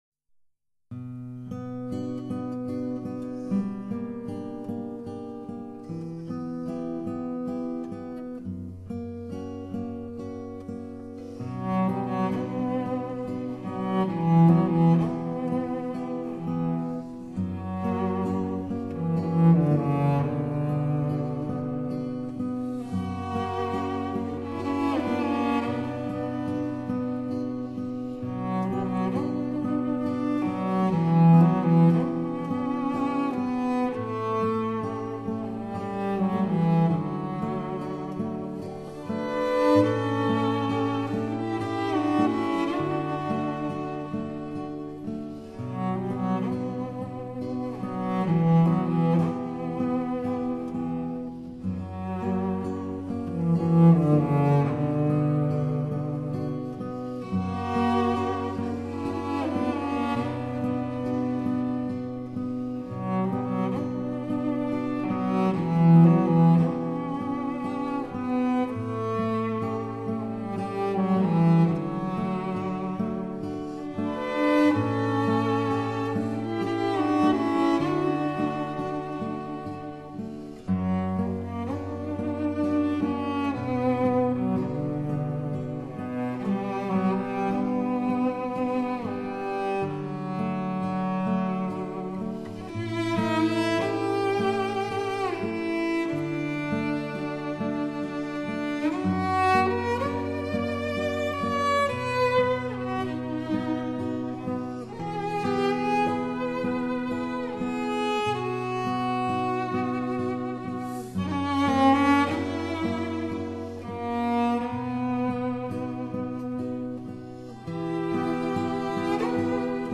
当高品质灵敏度的真空管麦克风遇上细腻温暖的大提琴声，让这悠扬的乐韵得以铭刻传颂到永远！
大提琴醇厚绵长的音色，瑰丽多姿的弓弦音乐表现，给予心灵、情感最高贵、最体贴的享受。